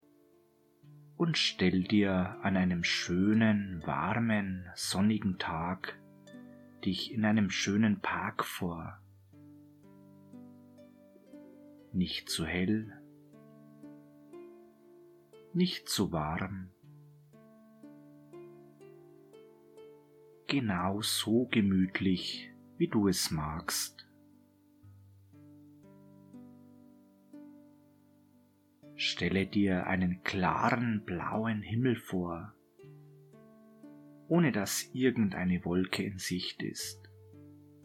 Sie erhalten mit diesem Paket zwei geführte Hypnosen: